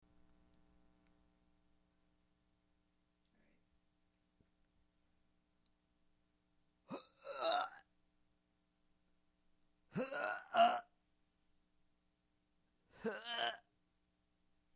Download Retch sound effect for free.
Retch